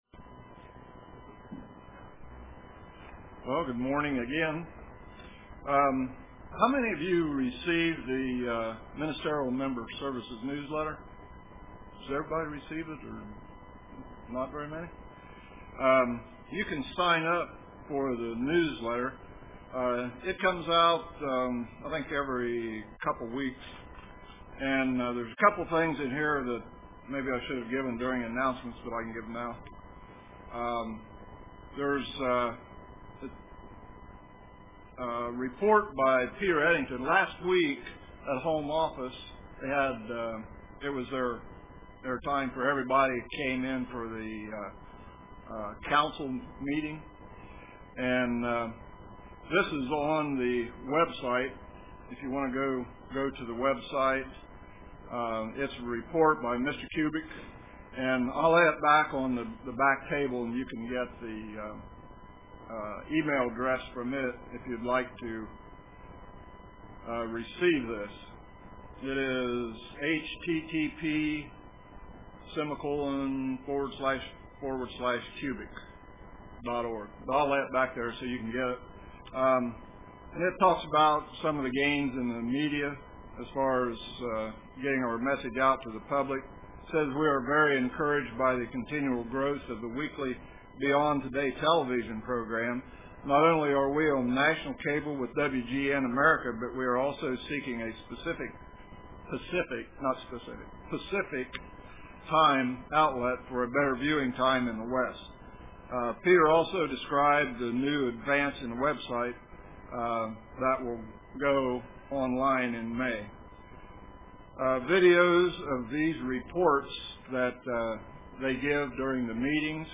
Print How can we prepare for the Passover? How can we prepare for the Passover UCG Sermon Studying the bible?